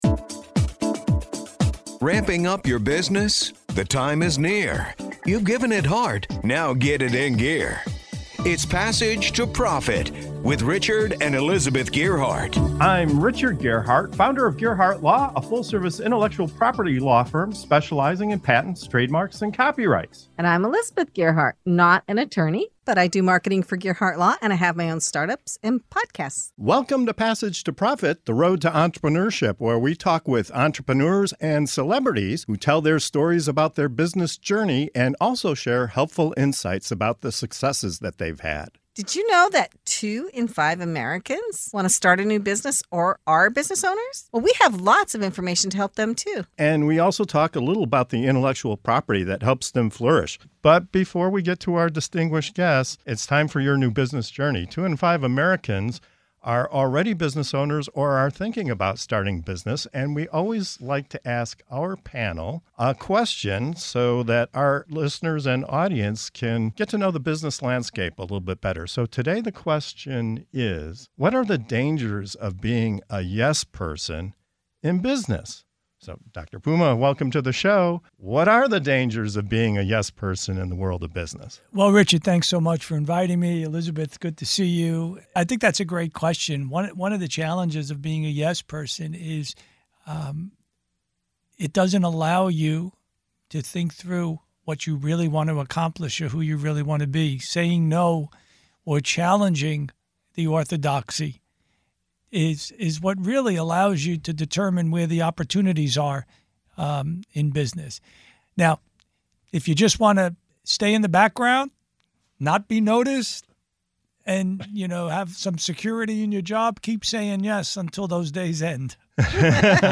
In this thought-provoking segment of “Your New Business Journey” on the Passage to Profit Show, our panel dives into the risks of always saying "yes" in the workplace. From stifled innovation and echo chambers to missed opportunities for personal growth, our experts explore how embracing disagreement can spark success.